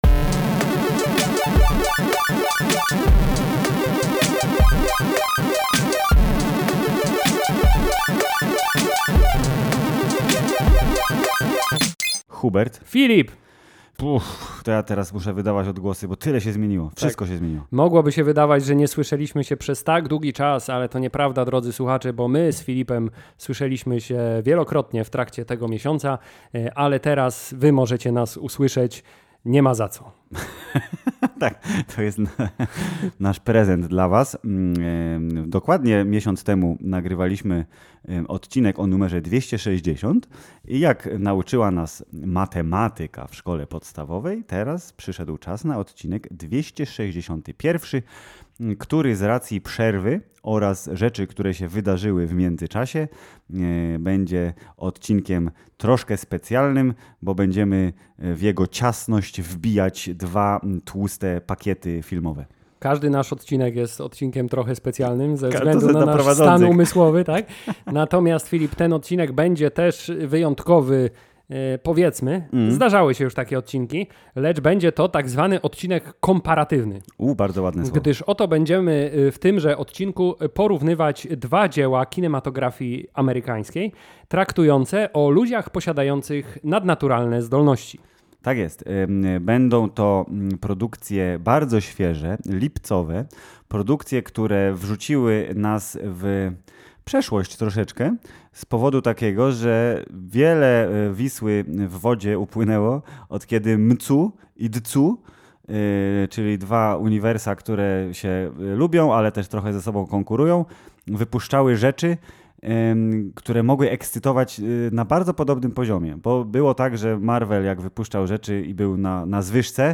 … continue reading 429 епізодів # Popkultura # Społeczeństwo # Polski # Film # Filmy # Seriale # Recenzje # Rozmowy # Kino # Serial # Rozrywka # Hammerzeit # Polsku